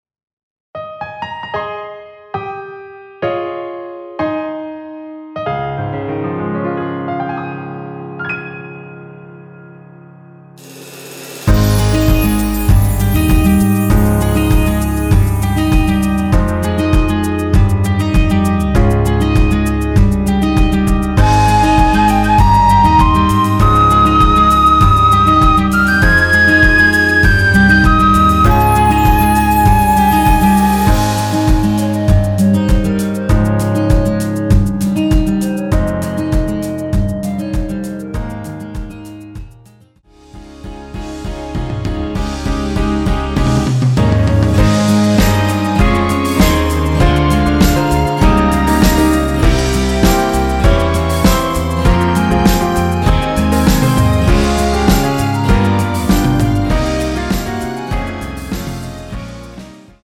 원키 멜로디 포함된 MR입니다.(미리듣기 확인)
Ab
앞부분30초, 뒷부분30초씩 편집해서 올려 드리고 있습니다.